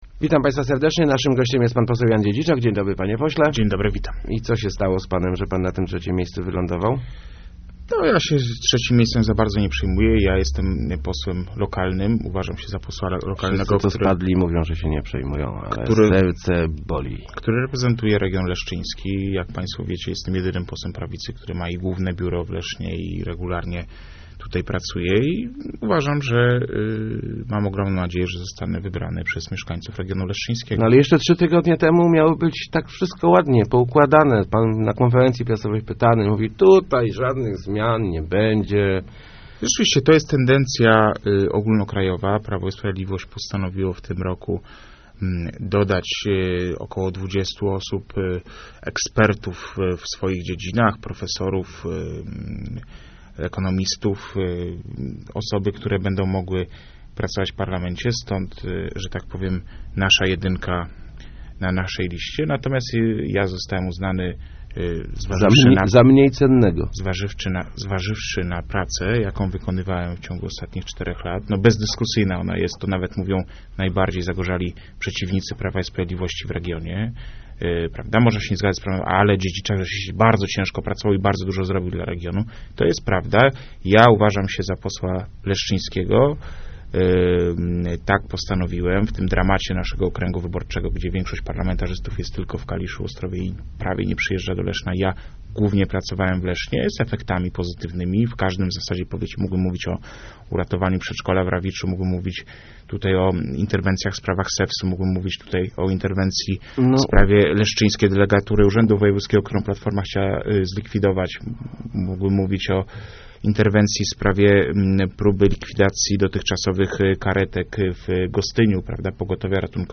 Mam bardzo dobre relacje z prezesem Kaczyńskim - mówił w Radiu Elka poseł PiS Jan Dziedziczak, komentując swoje trzecie miejsce na liście. Zdaniem Dziedziczaka wyborcy ziemi leszczyńskiej na niego zagłosują, ponieważ przez ostatnie lata z sukcesami pracował w naszym regionie.